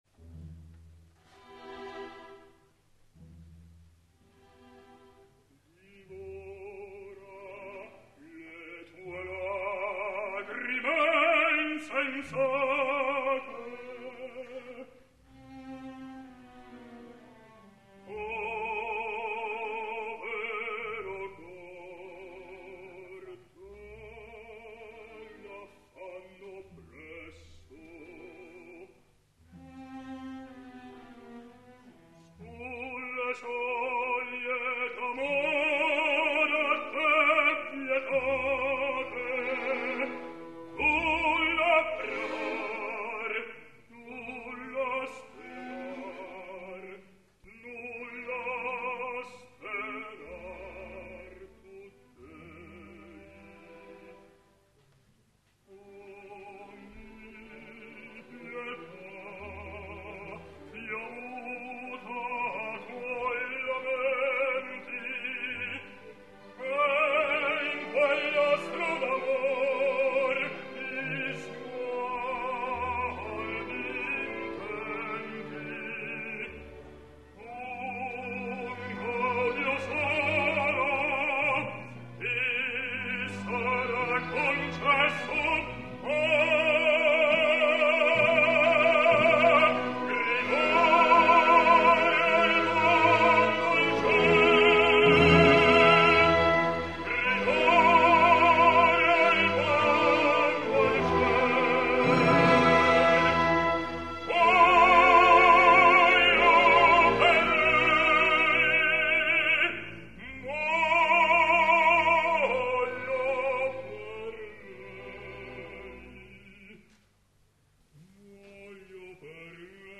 Ulmo [Bariton]